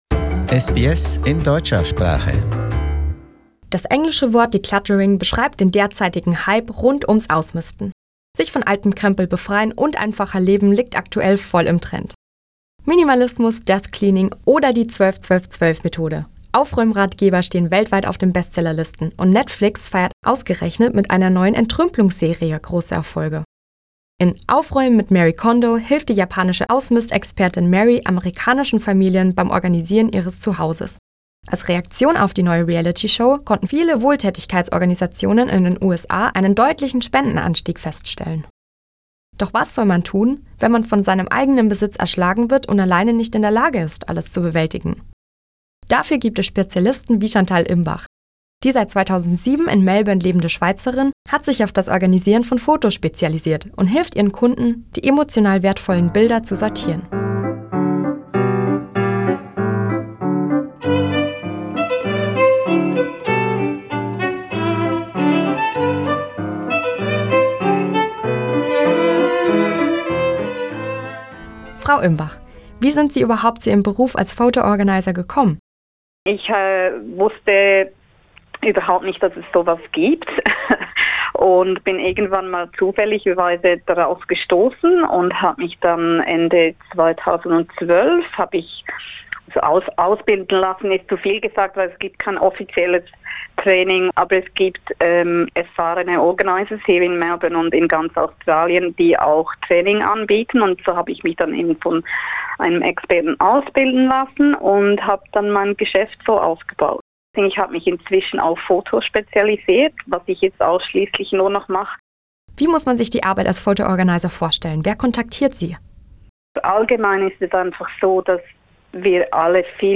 In this interview, she talks about her work and gives tips how you can organize your photo collection.